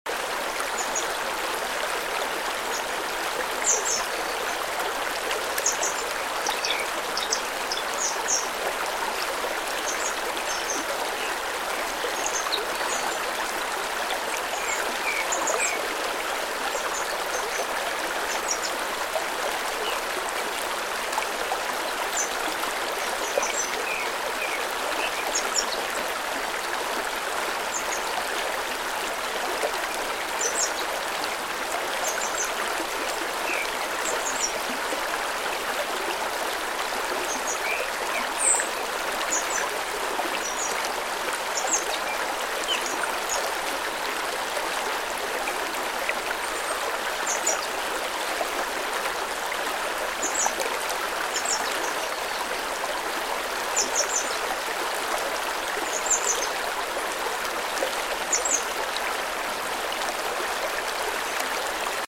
🌿 Nature ASMR
The stream and birdsong from the deep forest will soothe your tired mind.